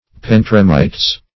Search Result for " pentremites" : The Collaborative International Dictionary of English v.0.48: Pentremites \Pen`tre*mi"tes\, n. [NL., from Gr.
pentremites.mp3